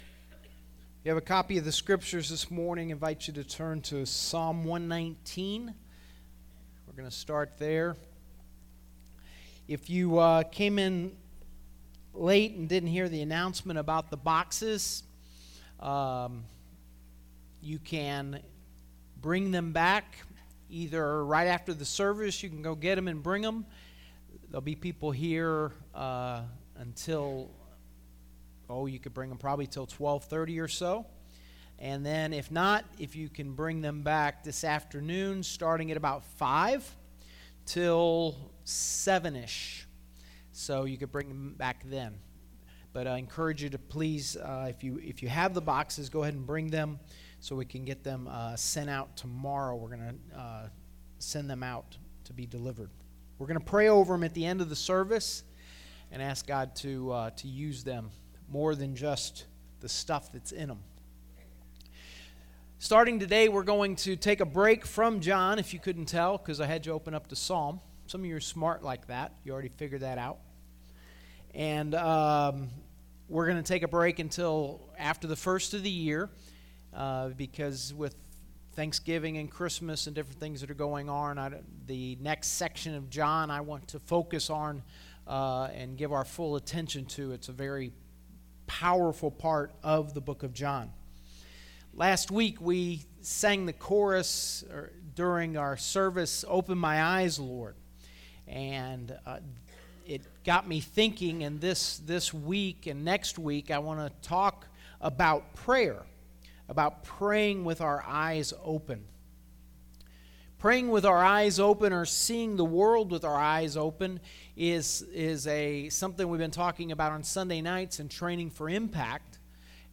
8 13 23 Sermon